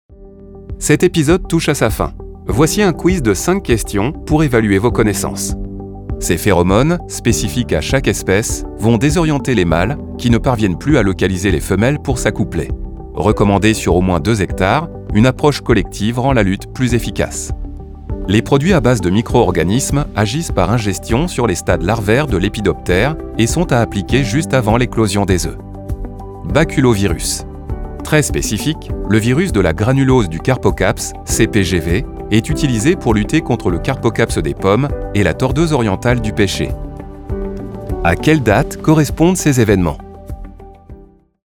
Naturelle, Fiable, Amicale, Chaude, Corporative
E-learning
He has a professional recording studio and is highly responsive.